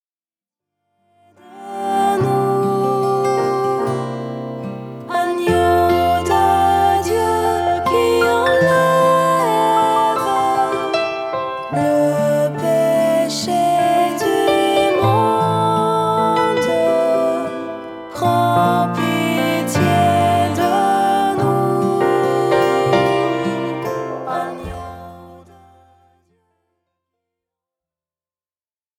Usage : Liturgie
Critères liturgiques : Messe - Agnus